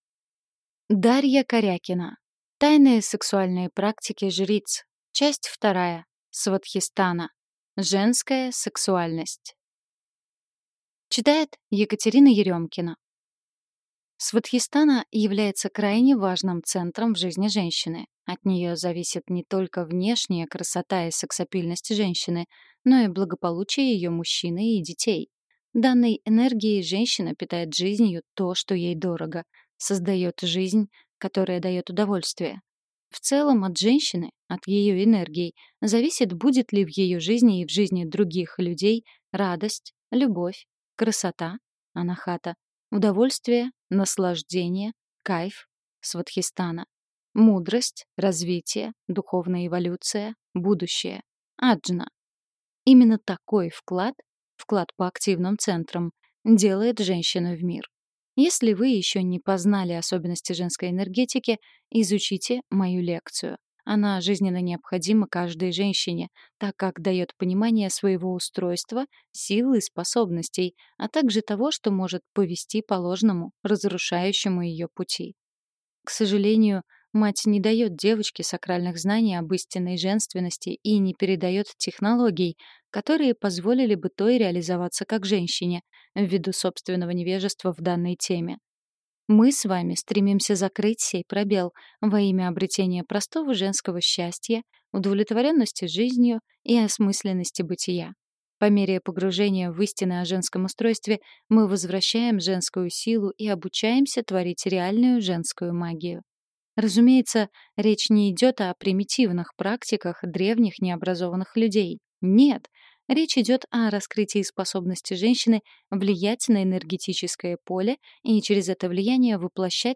Аудиокнига Тайные сексуальные практики Жриц. Часть 2. Свадхистана. Женская сексуальность.